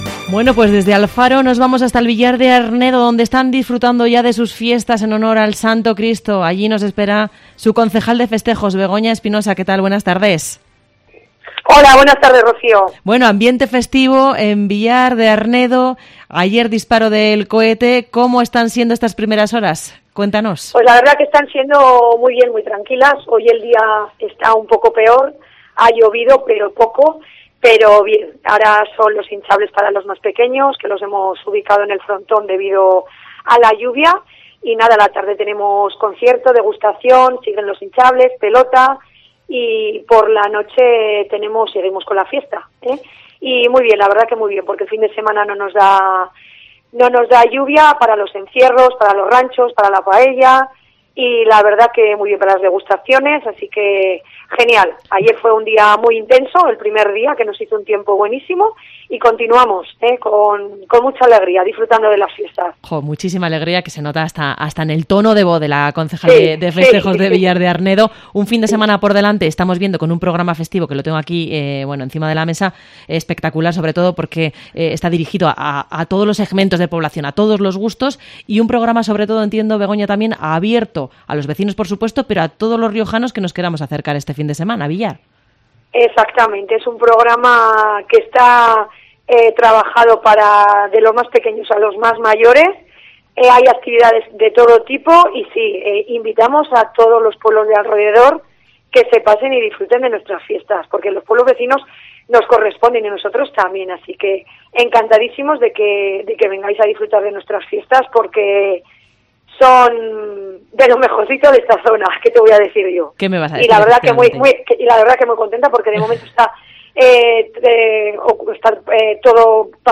COPE se ha trasladado hasta El Villar de Arnedo para darte a conocer el variado programa festivo que ha elaborado su Ayuntamiento. Su concejal de Festejos, Begoña Espinosa, nos invita a disfrutar de unas fiestas que están pensadas para el disfrute de todos.